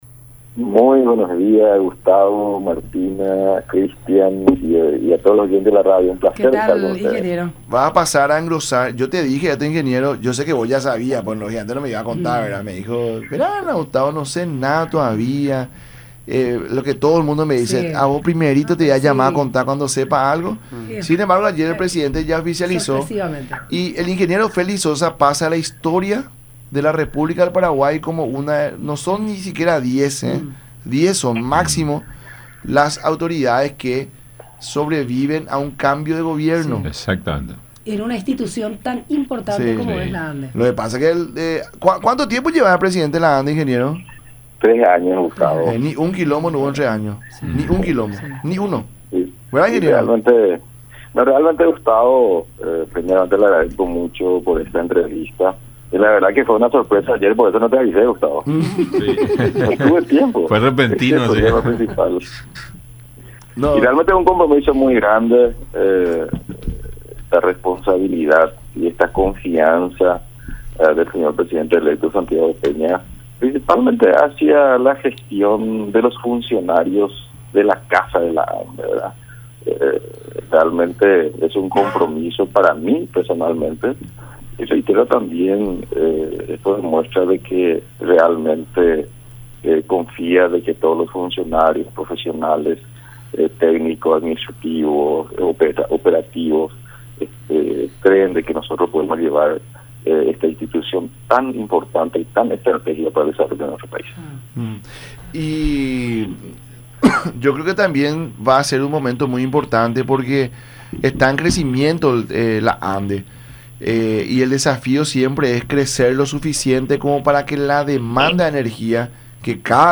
“Es un compromiso muy grande de responsabilidad por la confianza de Santiago Peña, principalmente hacia la gestión de funcionarios de la caja de Ande”, mencionó Félix Sosa en conversación con el programa “La Mañana De Unión” por  Unión TV y radio La Unión.